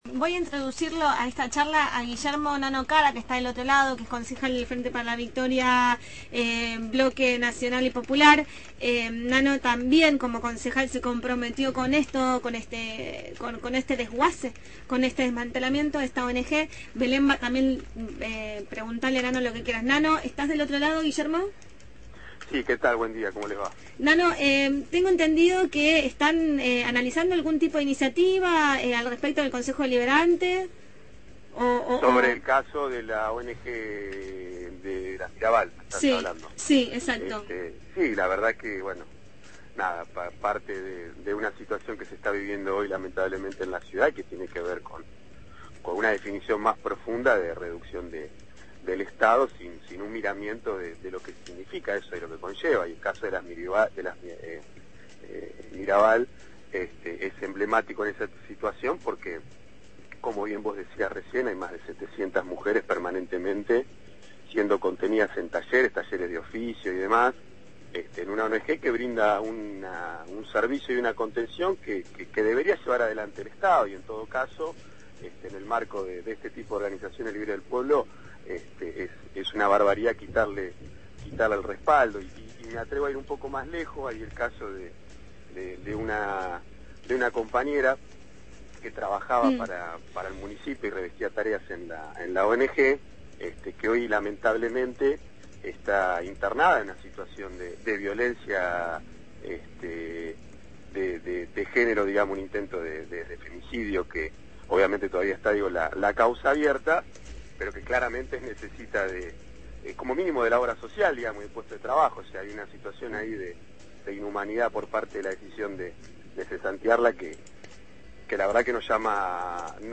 Guillermo «Nano» Cara, concejal de La Plata por el FpV.